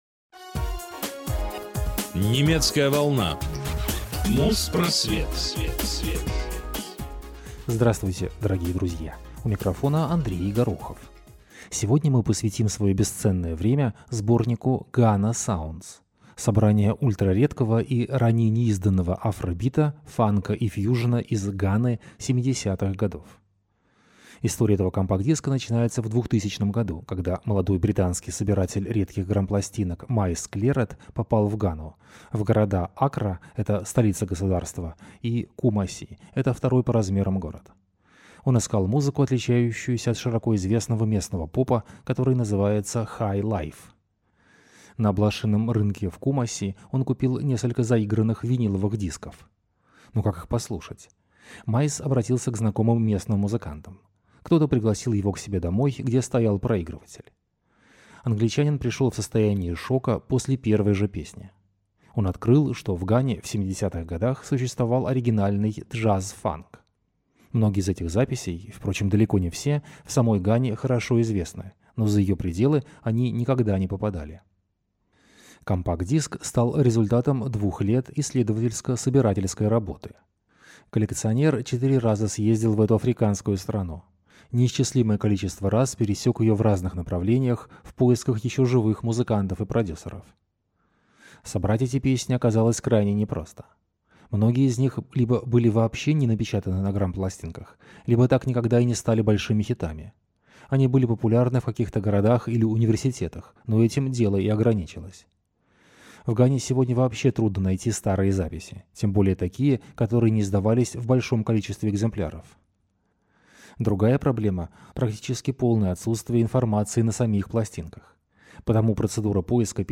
Музпросвет 287 от 9 февраля 2008 года - Фанк и афро-бит в Гане в 70-х | Радиоархив